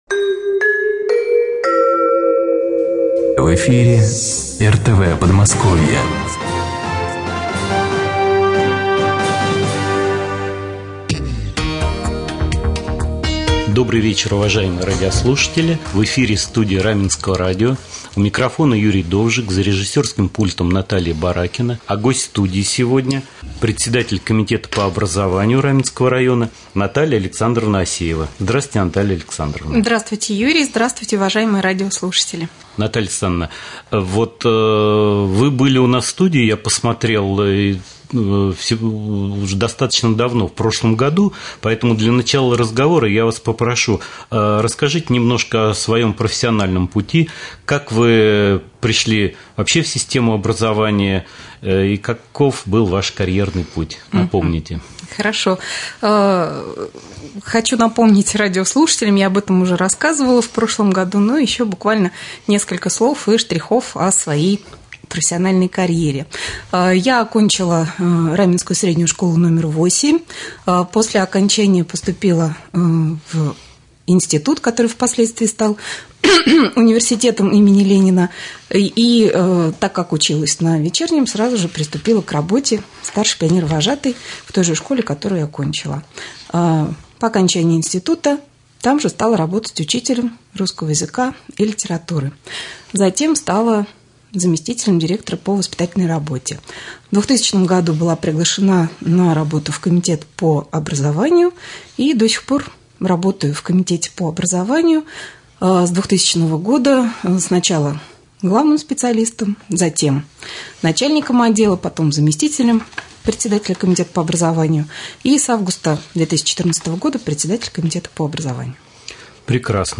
Прямой эфир с председателем комитета по образованию администрации района Натальей Асеевой